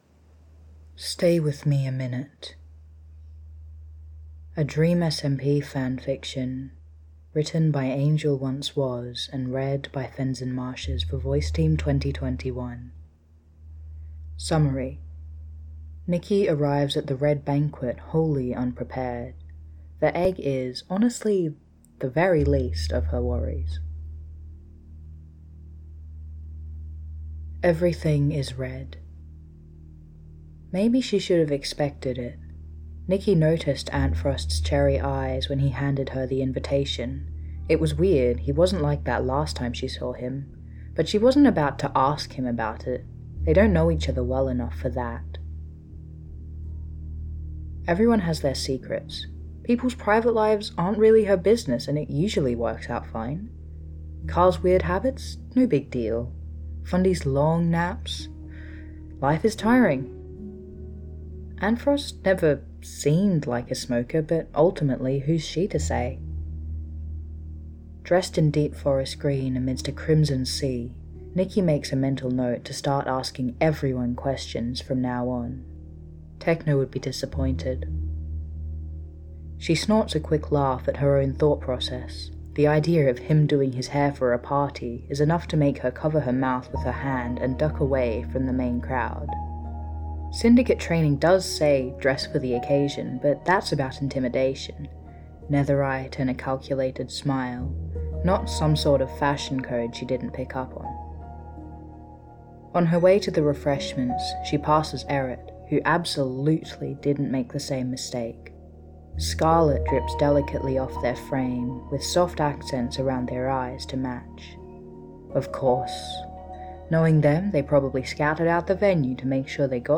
with music: